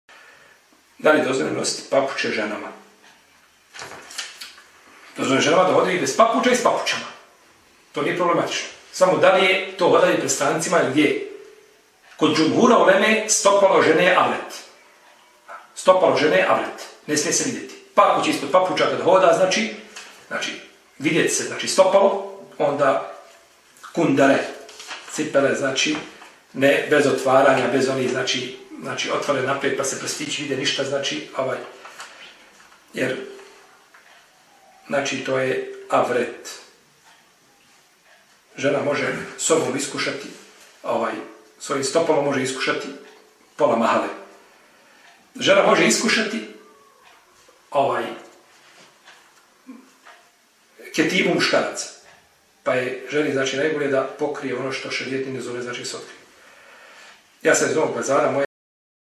Poslušajte audio – mp3 isječak odgovora